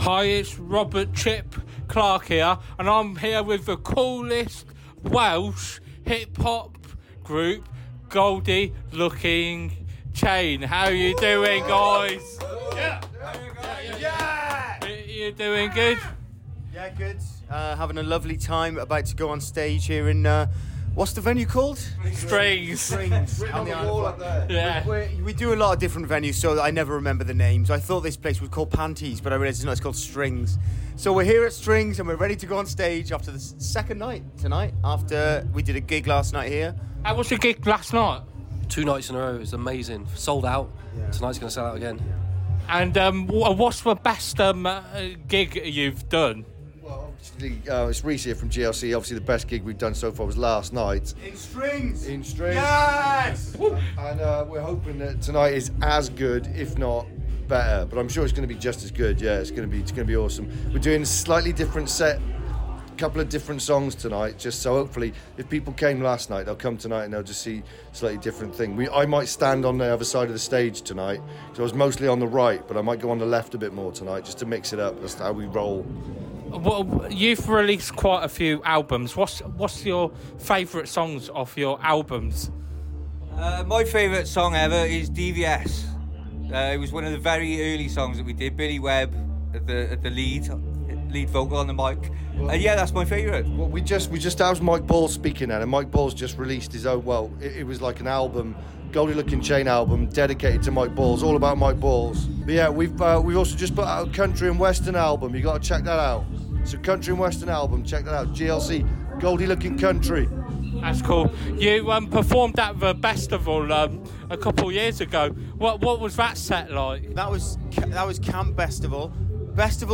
Goldie Lookin Chain Interview 2024